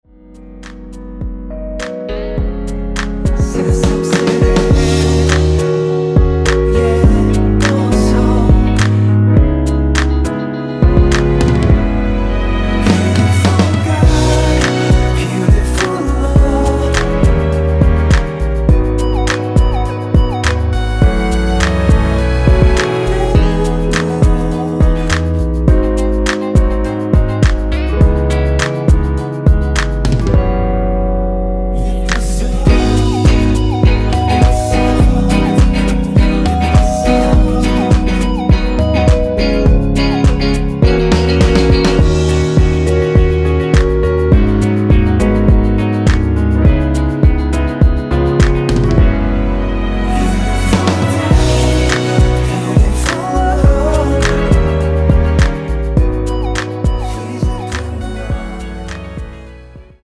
원키에서(-1)내린 코러스 포함된 MR이며 랩은 포함되지 않습니다.(미리듣기 참조)
앞부분30초, 뒷부분30초씩 편집해서 올려 드리고 있습니다.
중간에 음이 끈어지고 다시 나오는 이유는